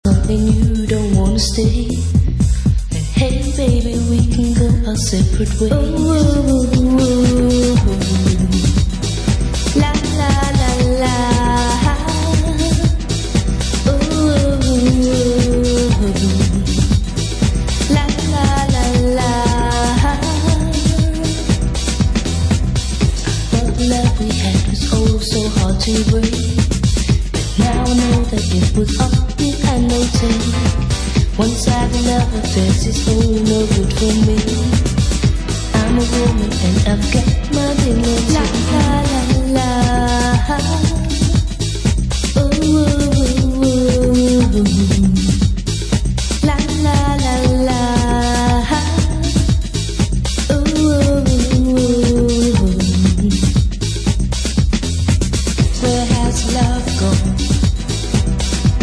】UKメイドのアシッド・ハウスや初期ハウスをコンパイルした強力コンピレーション！